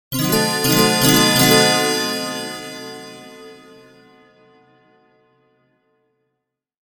Magic-transition-chime.mp3